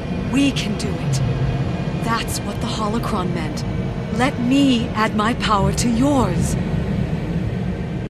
―Leia Organa Solo, to Luke Skywalker — (audio)